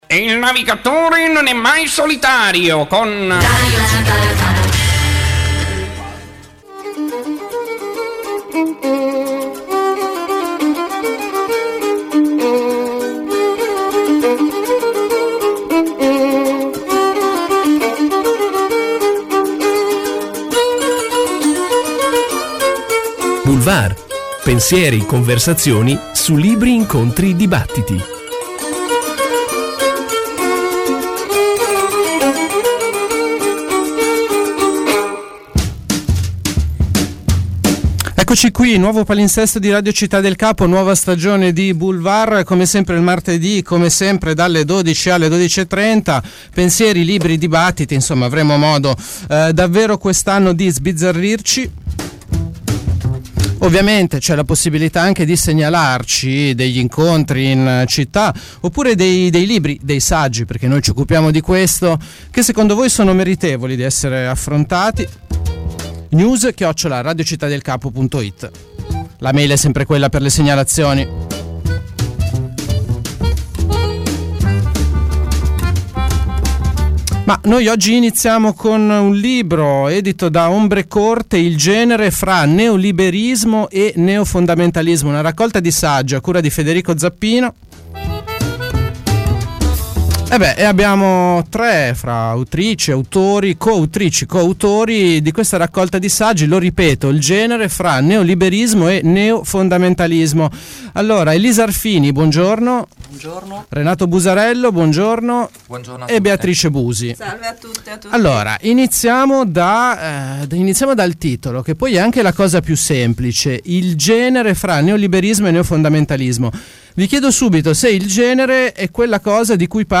Li abbiamo intervistati all’interno della trasmissione di Radio Città del Capo Boulevard.